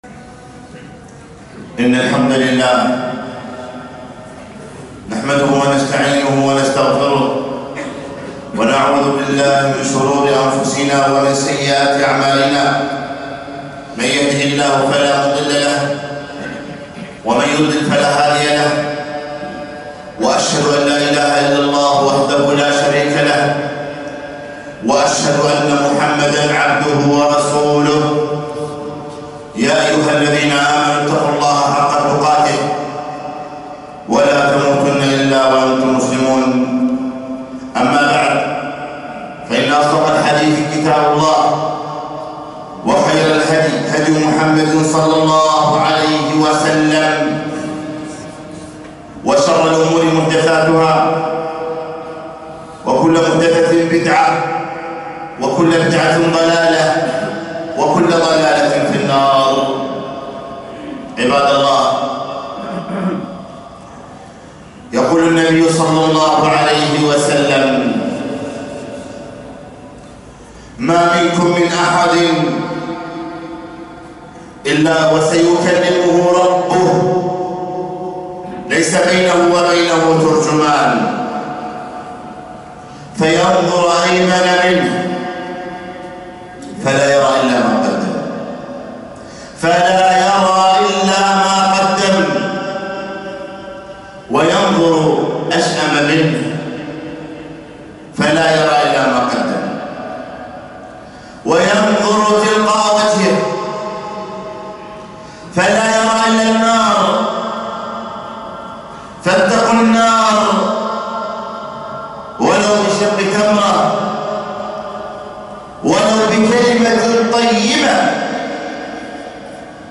خطبة - يوم ينظر المرء ما قدمت يداه